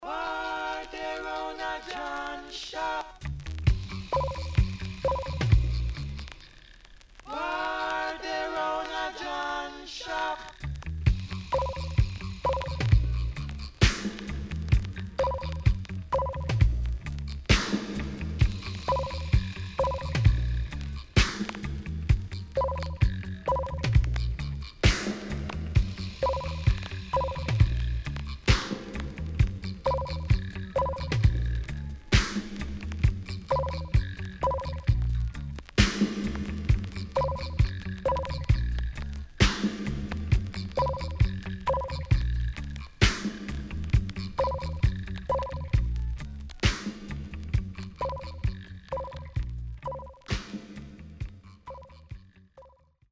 渋Vocal
SIDE A:プレス起因？で全体的にチリノイズがあり、少しプチノイズ入ります。